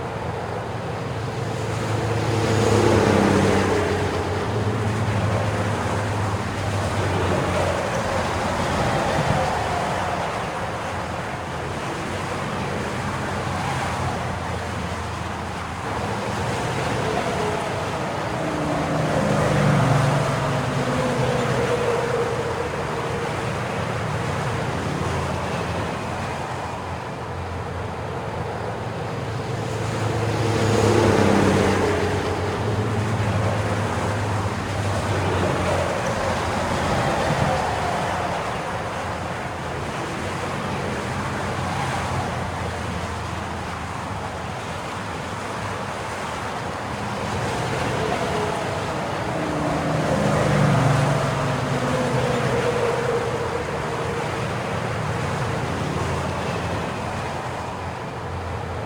road.ogg